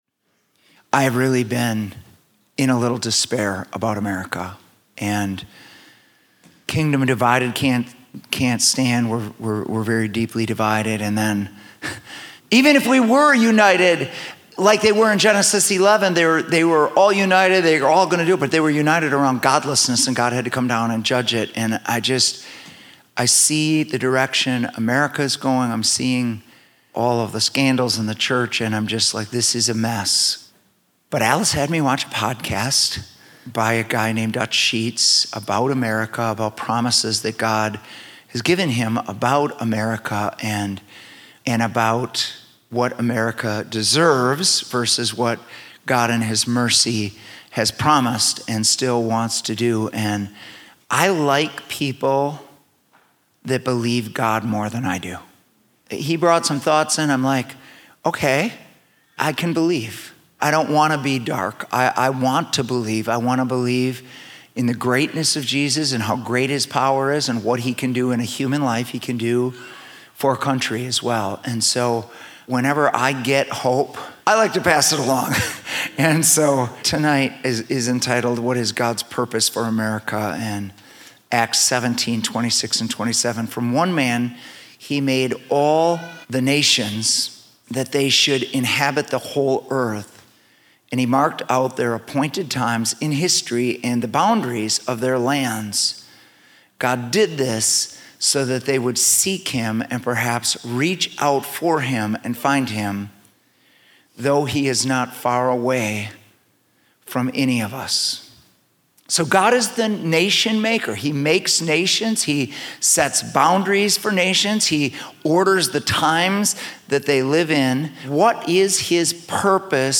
Stand Alone Messages @ City Church